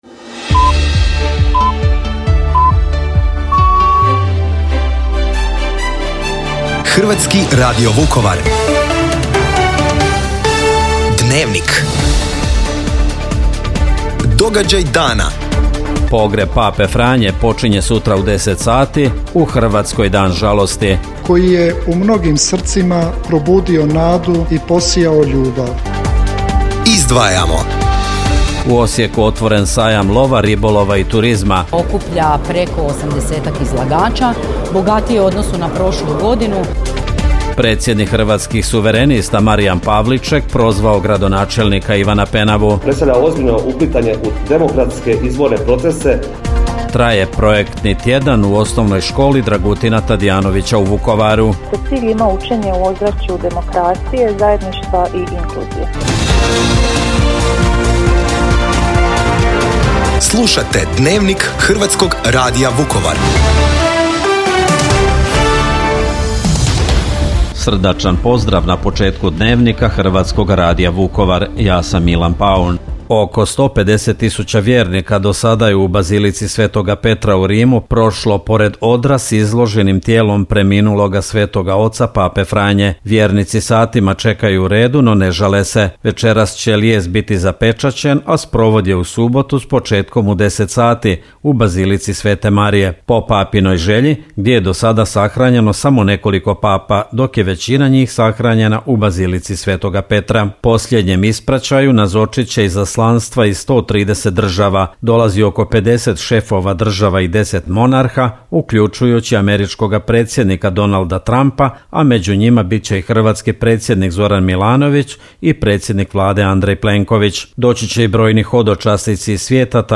Dnevnik, petak, 25. travnja 2025.